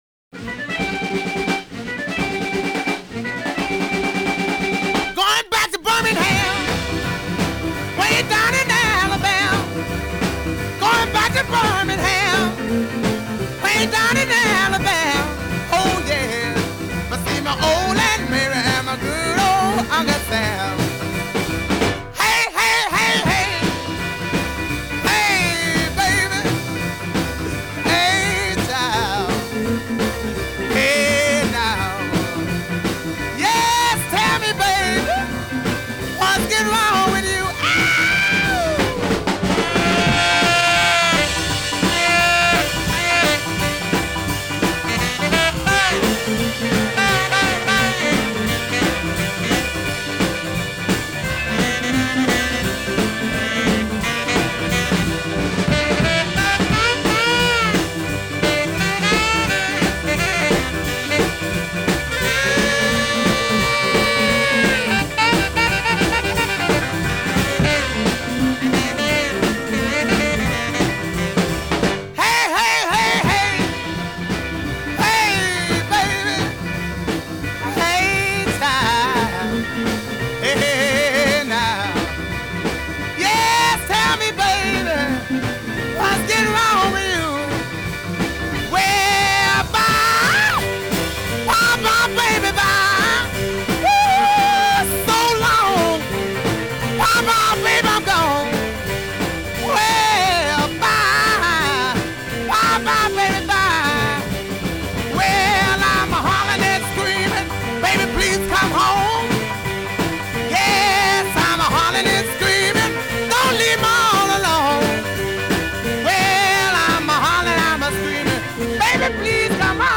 Category: studio outtakes
An out-and-out rocker